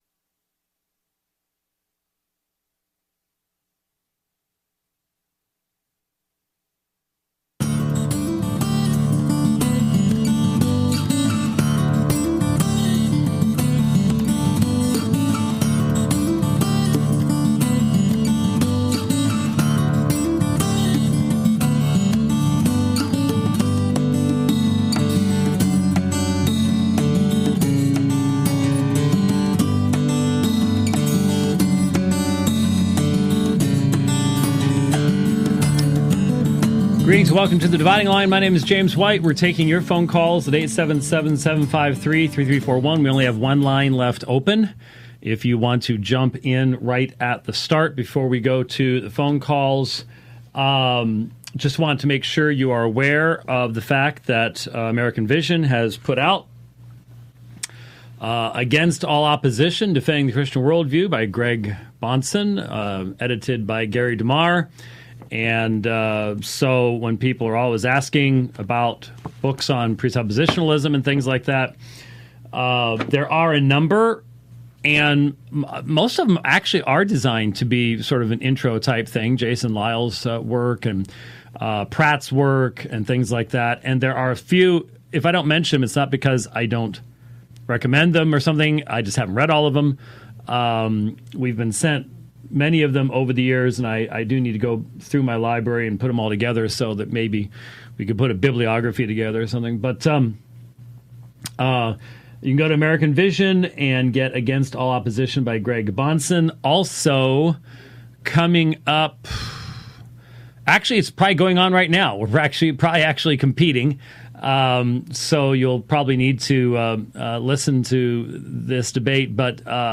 Four Open Phone Calls, A..